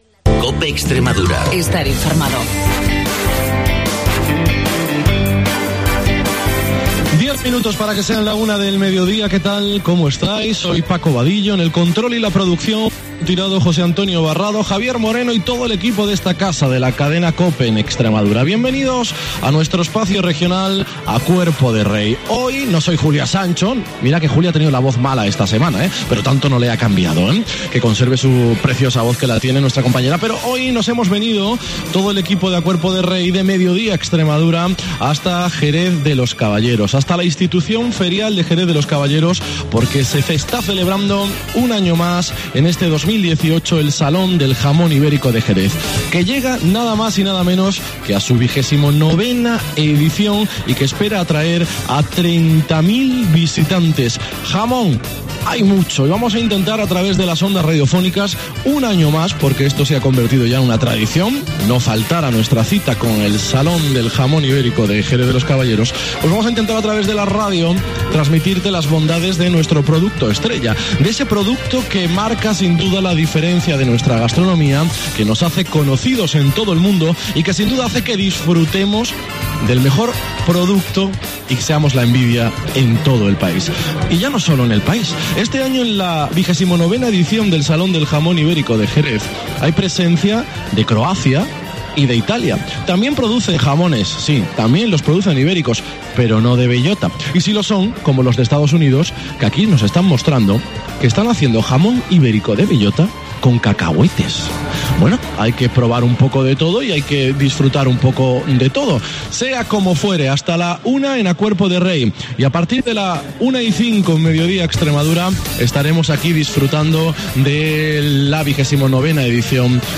AUDIO: Especial A Cuerpo de Rey y MEDIODÍA Extremadura desde el XIX Salón del Jamón Ibérico de Jerez de los Caballeros